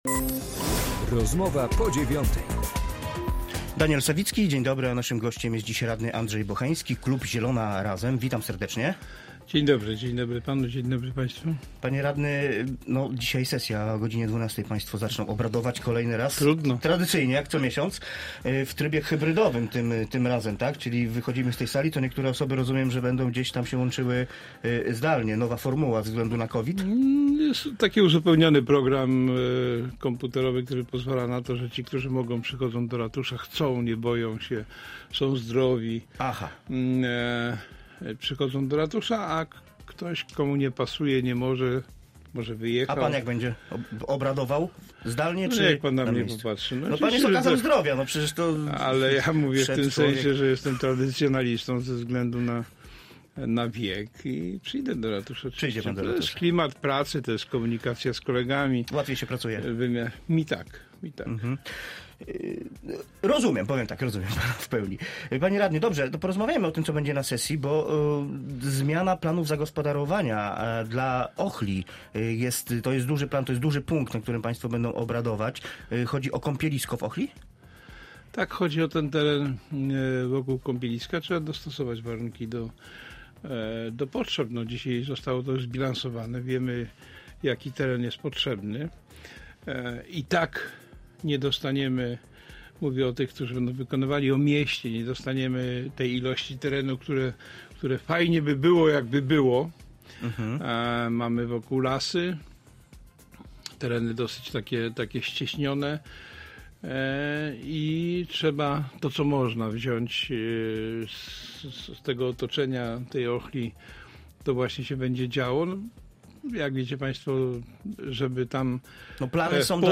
Andrzej Bocheński, radny klubu Zielona Razem